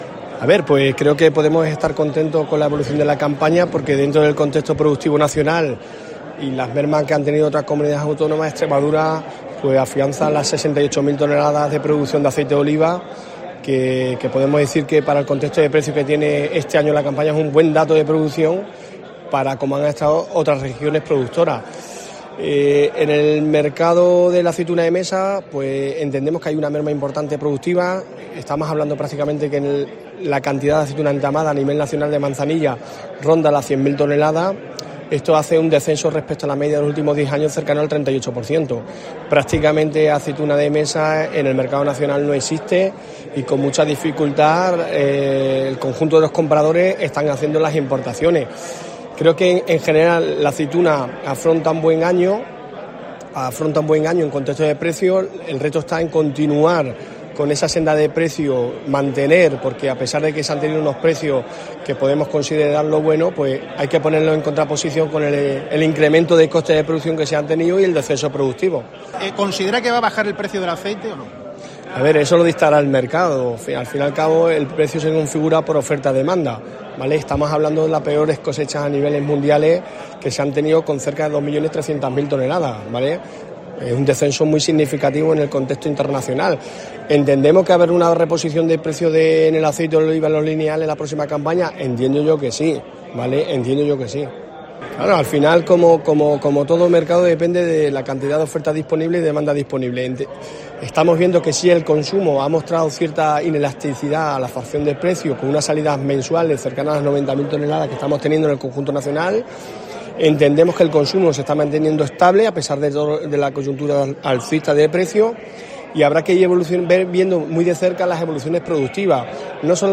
En COPE, con motivo del Salón del Vino y la Aceituna de Extremadura, que se clausuró el viernes, hemos hablado con una de las voces más autorizadas en la región